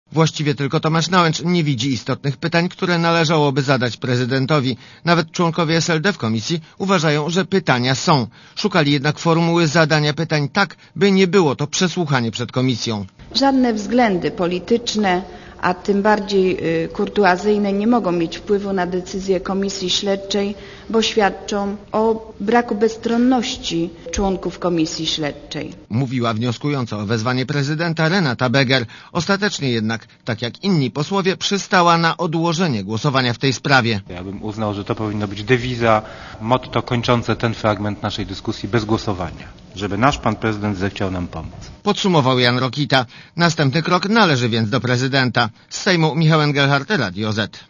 Relacja reportera Radia Zet (188kb)